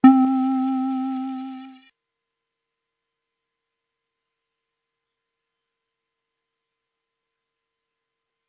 描述：Wood Bass
标签： Bass Wood c simple
声道立体声